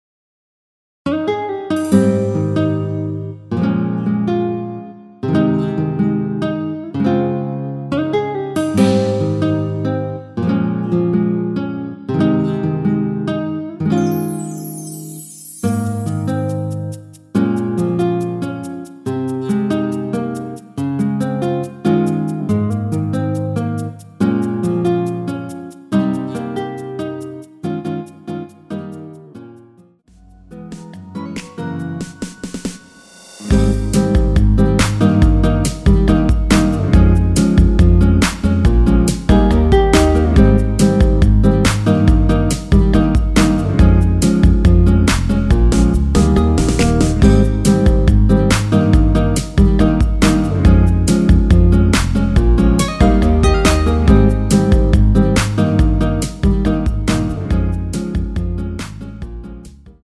Ab
◈ 곡명 옆 (-1)은 반음 내림, (+1)은 반음 올림 입니다.
앞부분30초, 뒷부분30초씩 편집해서 올려 드리고 있습니다.
중간에 음이 끈어지고 다시 나오는 이유는